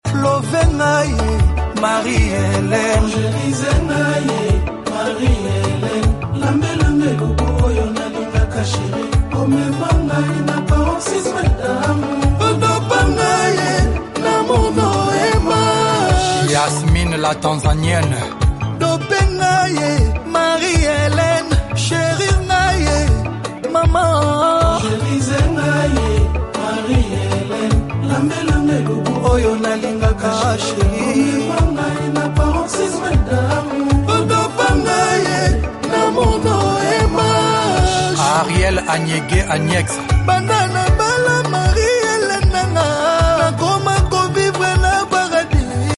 rhumba